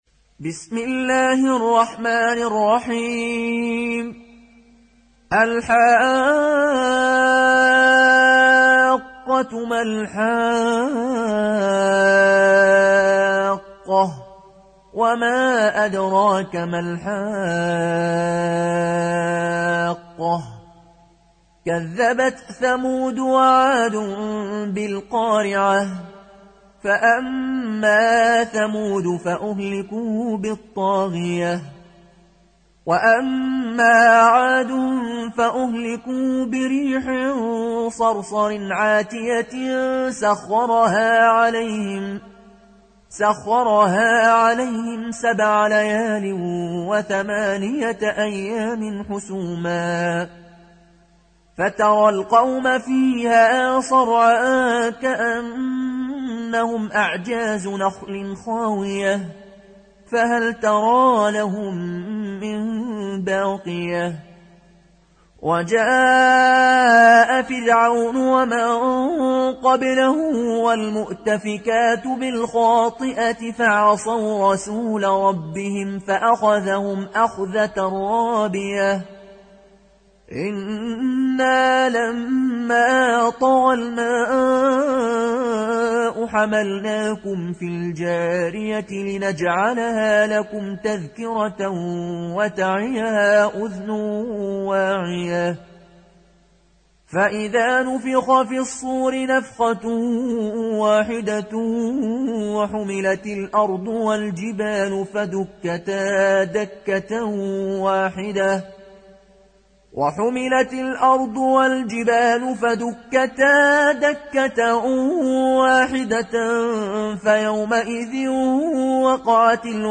(Riwayat Qaloon)